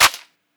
Bruce Lee Clap
Bruce-Lee-Clap.wav